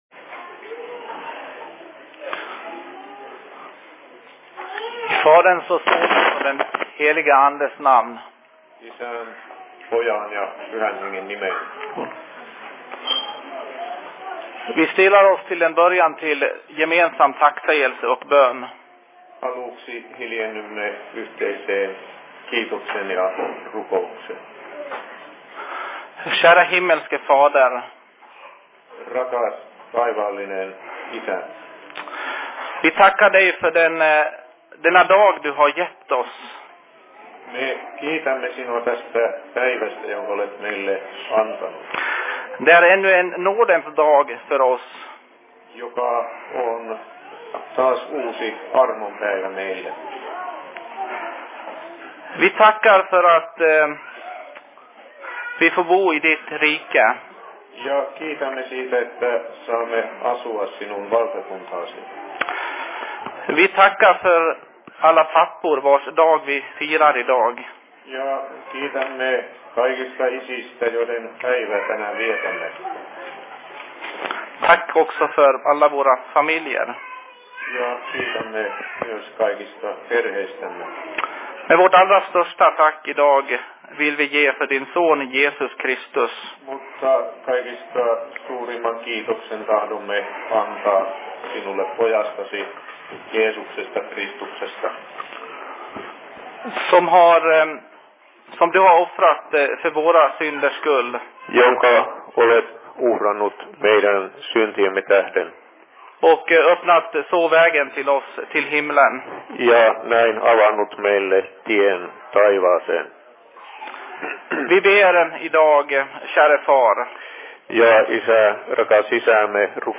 Se Fi Predikan I Dalarnas Fridsförening 13.11.2011
Paikka: SFC Dalarna
Simultaanitulkattu